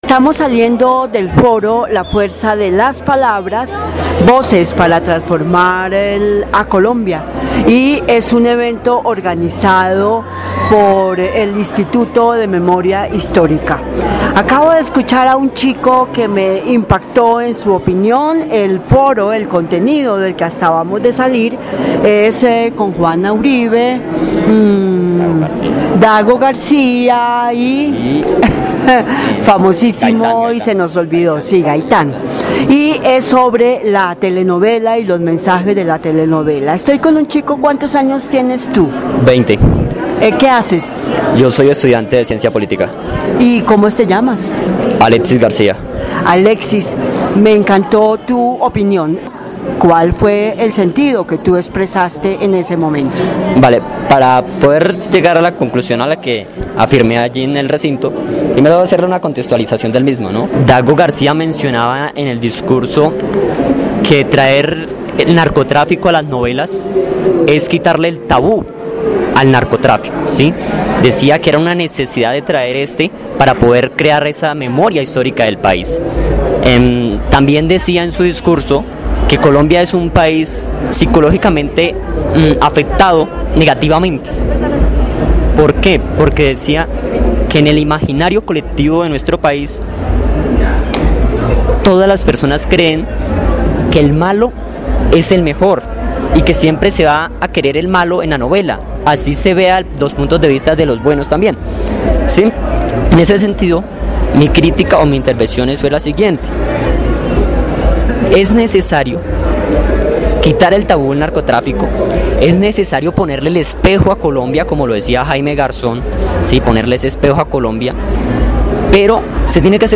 Pero, en esta ocasión hablamos con un asistente.